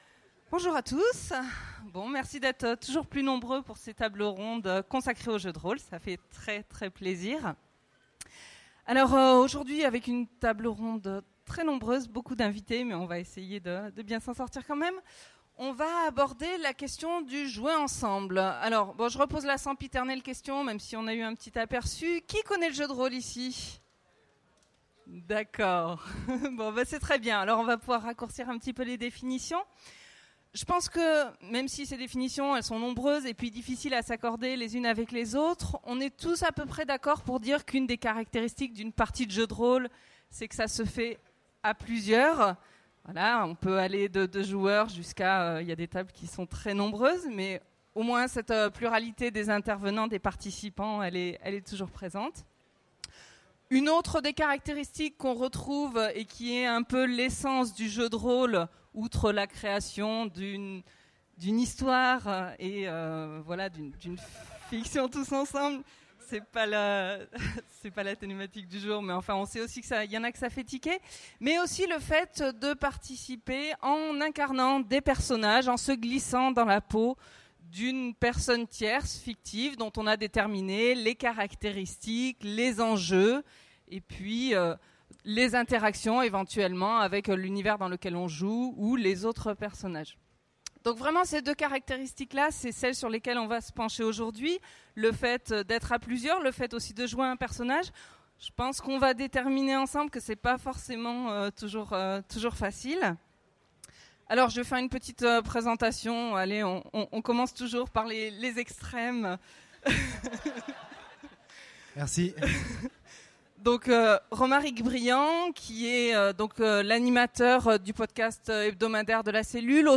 Utopiales 2015 : Conférence Jouer ensemble, lorsque l'on joue un personnage